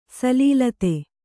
♪ salīlate